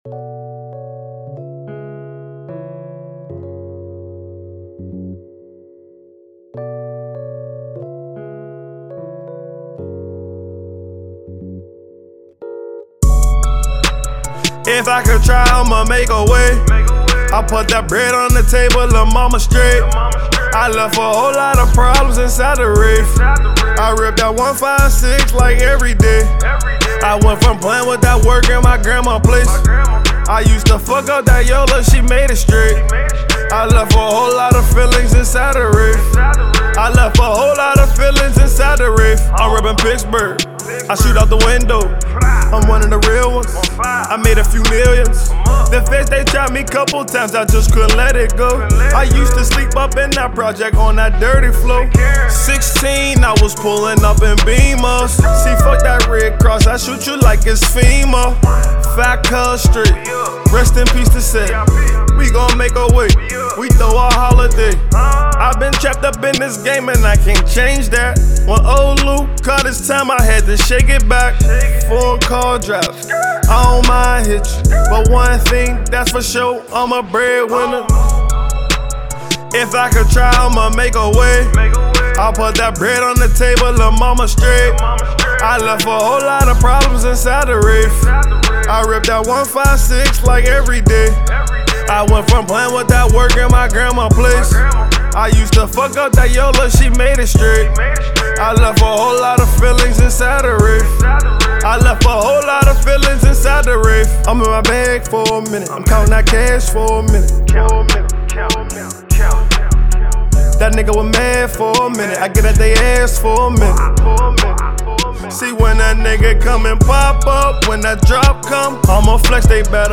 Hiphop
rapper and recording artist